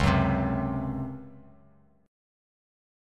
C#M7sus4 chord